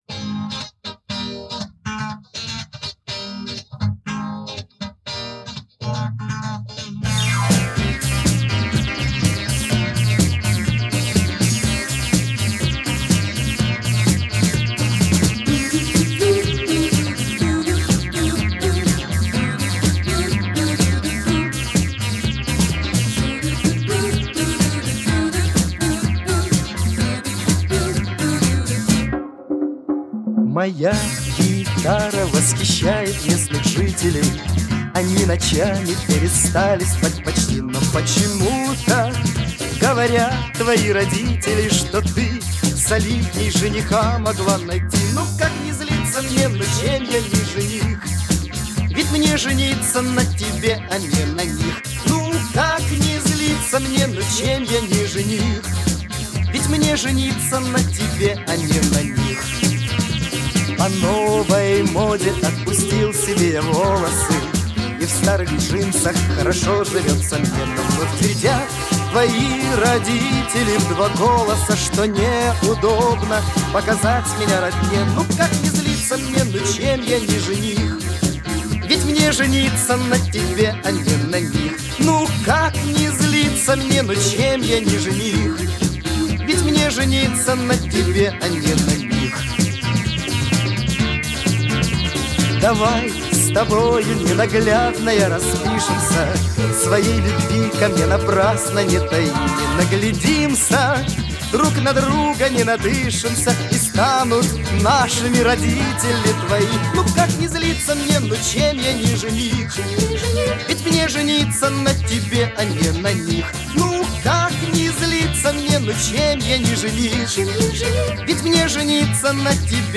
Жанр: Rock, Pop
Стиль: Vocal, Pop Rock, Schlager